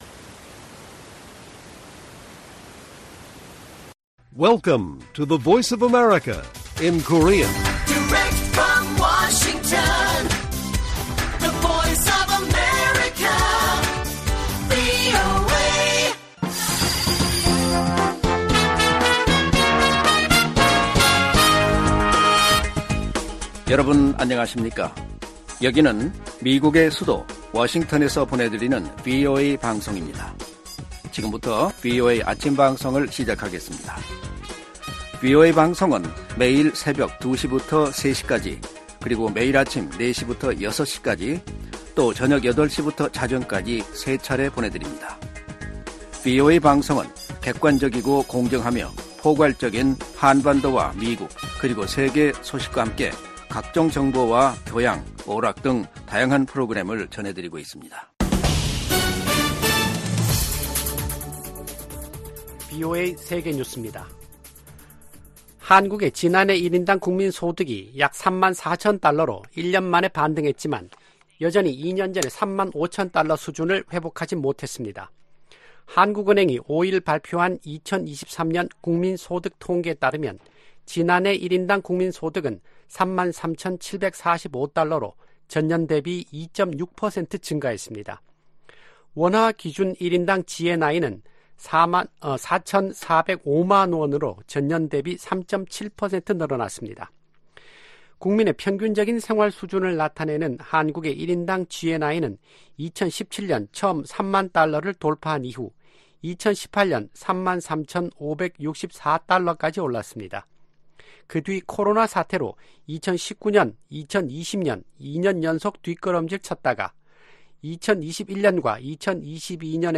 세계 뉴스와 함께 미국의 모든 것을 소개하는 '생방송 여기는 워싱턴입니다', 2024년 3월 6일 아침 방송입니다. '지구촌 오늘'에서는 리창 중국 총리가 전국인민대표대회(전인대) 업무보고에서 올해 경제 성장률 목표를 5% 안팎으로 제시한 소식 전해드리고, '아메리카 나우'에서는 미국 대선 경선의 하이라이트라고 할 수 있는 슈퍼화요일 이야기 살펴보겠습니다.